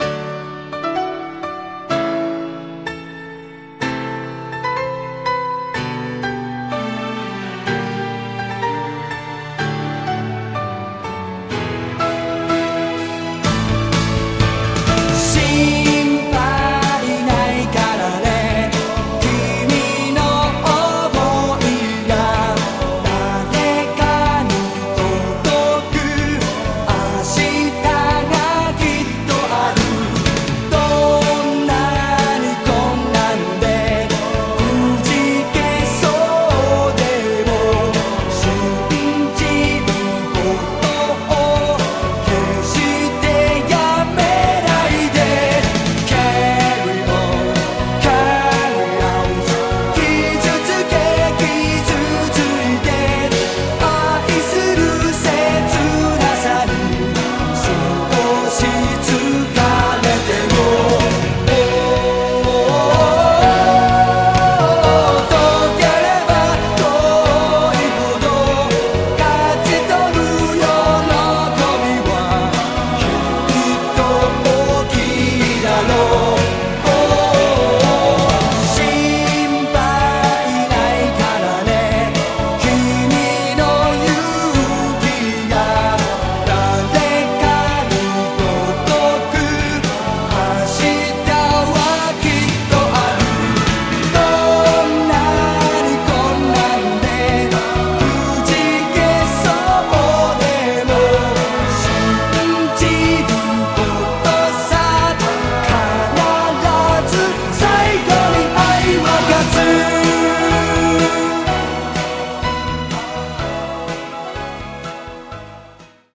BPM125
Audio QualityPerfect (High Quality)
An extra-catchy song